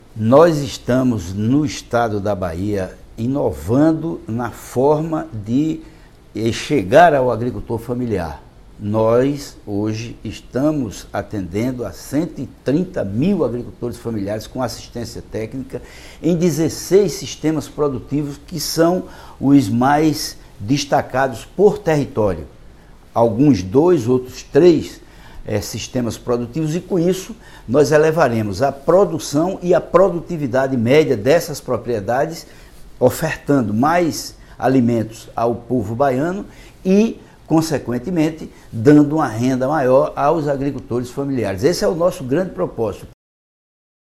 Sonora secretário de Desenvolvimento Rural Josias Gomes